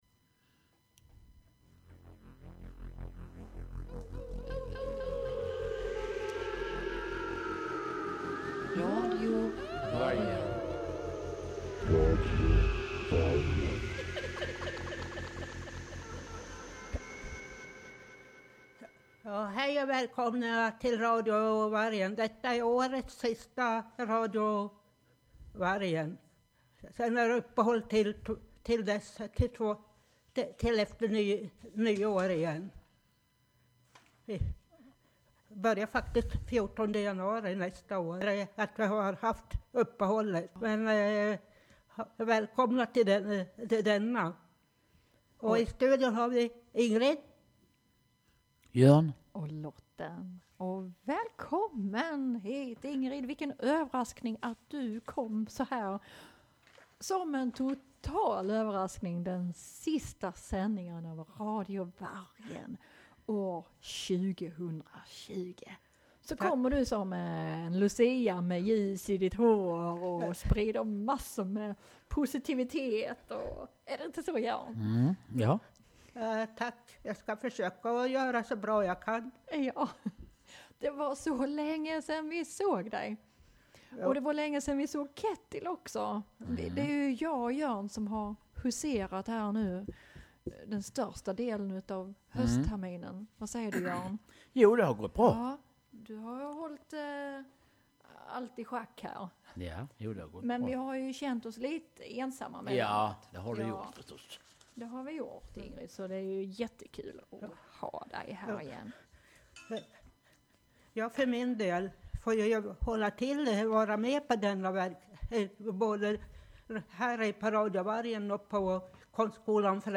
Vi drar lite tomteskämt, pratar om julfirande, SVT´s underhållning under julhelgen, nyårslöften samt spelar önskelåtar med jul-tema.
I studion: